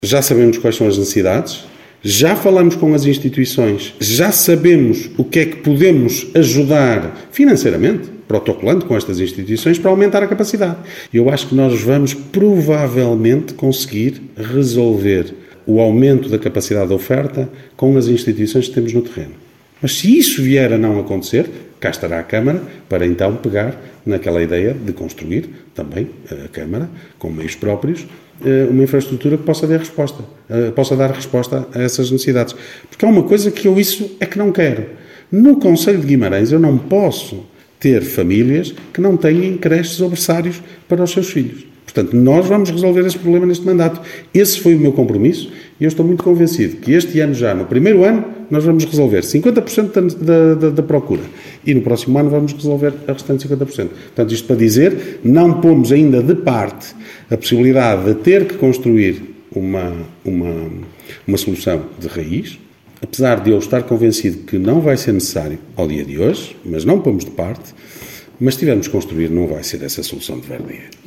Declarações de Ricardo Araújo, presidente da Câmara Municipal de Guimarães. Falava esta segunda-feira, à margem da reunião do executivo.